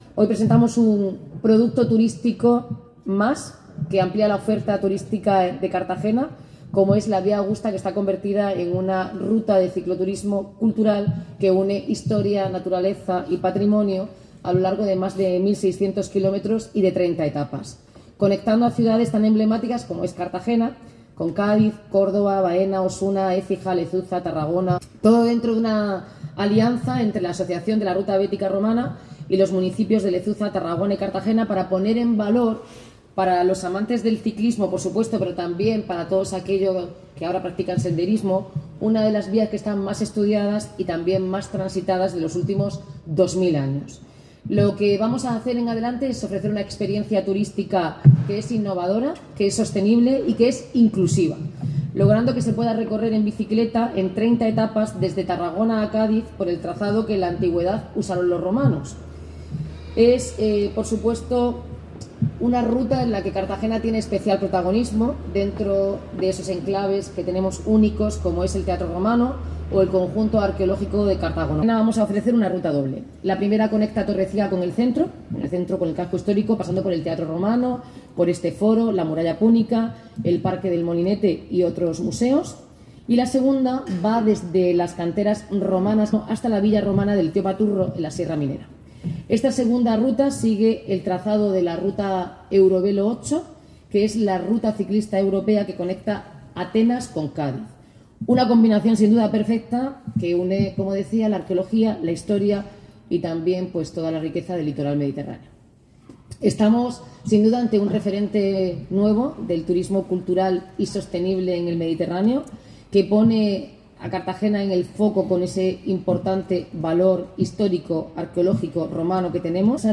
Enlace a Declaraciones de la alcaldesa, Noelia Arroyo, sobre proyecto Ciclo Vía Augusta
El Ayuntamiento de Cartagena ha presentado este lunes 24 de noviembre en el Foro Romano su participación en el producto turístico Ciclo Vía Augusta, desarrollado con financiación europea y que convertirá la antigua calzada romana en una de las rutas de cicloturismo cultural más importantes del país.